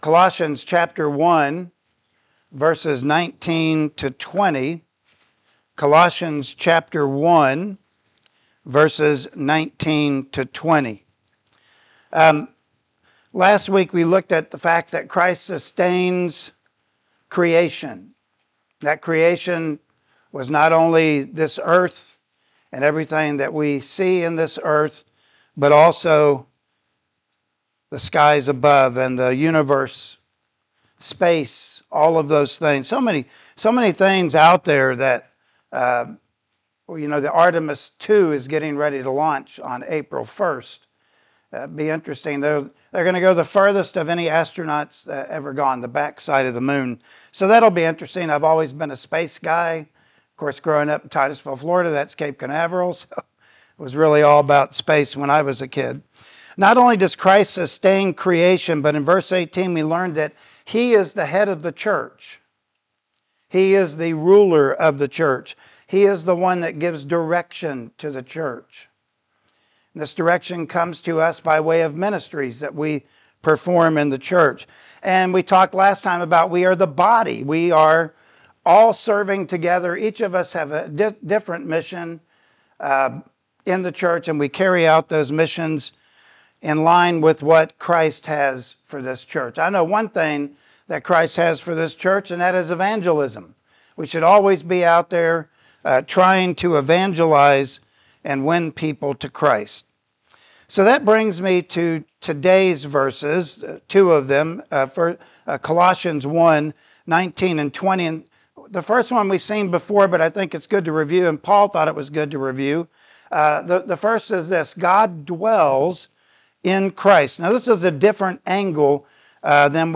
Colossians 1:19-20 Service Type: Sunday Morning Worship Service Topics: Reconciliation in Christ « Incomparable Christ